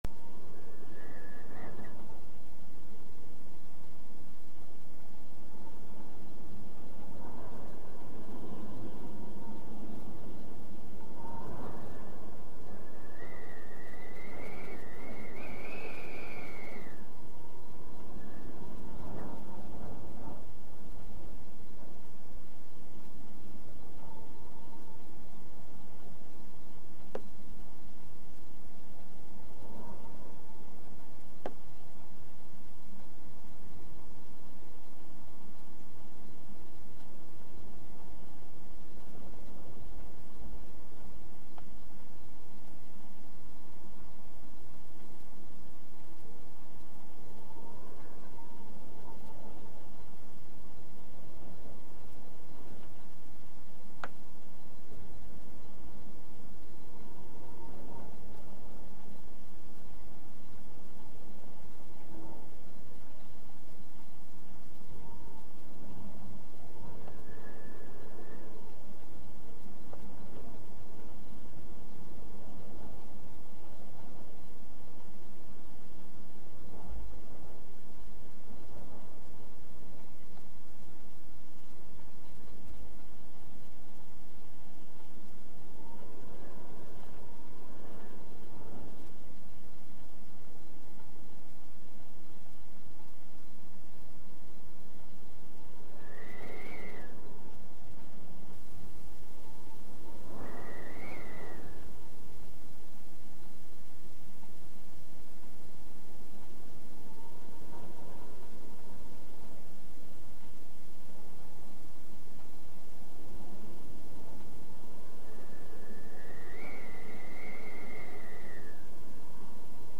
Windy Weather from inside my Bedroom Window yesterday